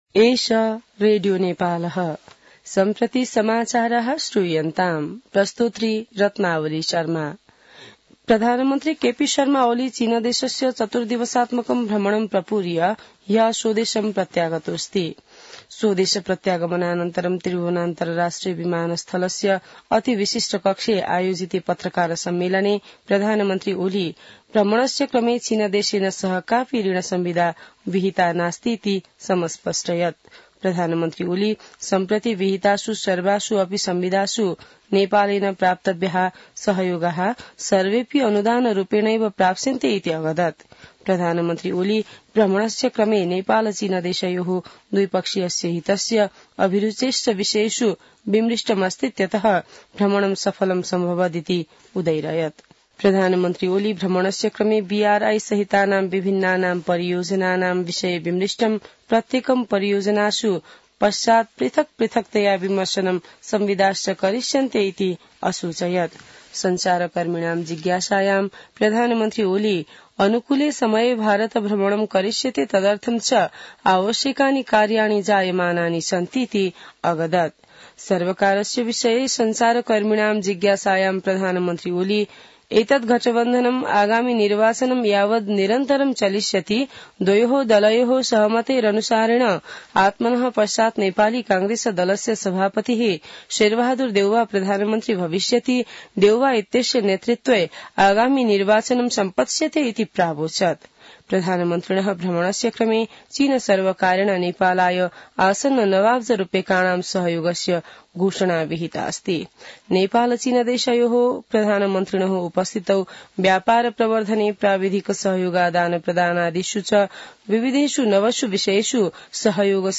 संस्कृत समाचार : २२ मंसिर , २०८१